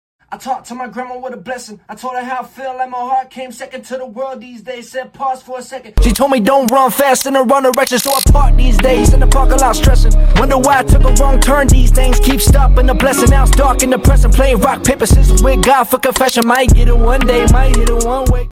a mellow vibe